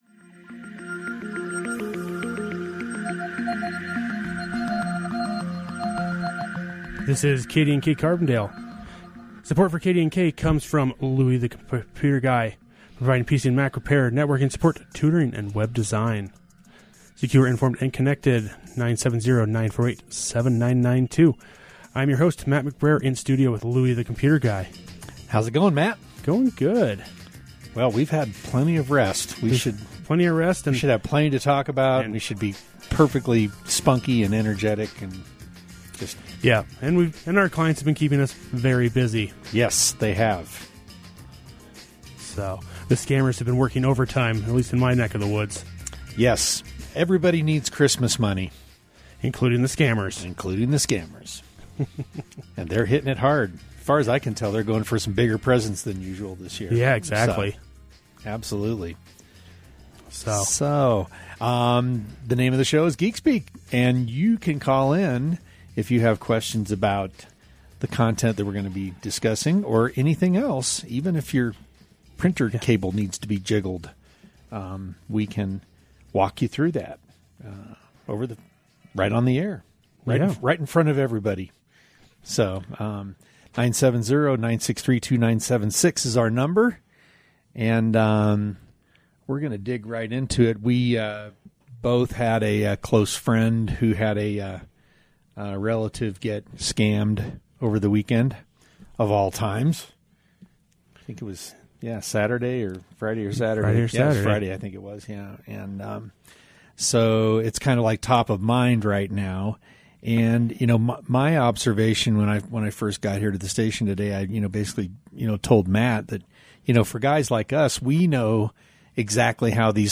A beloved call-in tech talk show